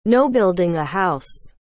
NO BUILDING A HOUSE google translate